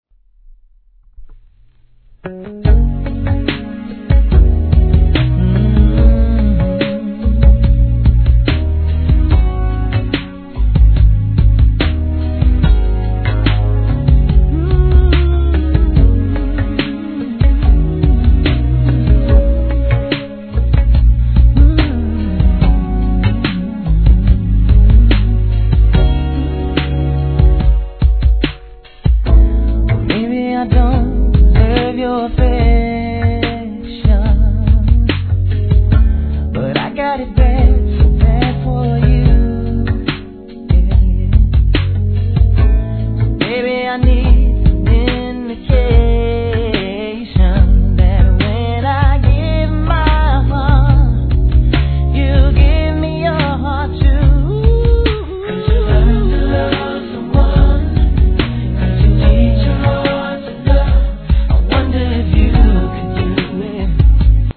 HIP HOP/R&B
最高の歌声とコーラスをBGMに春の木陰でティー・タイムなんていかがでしょうか？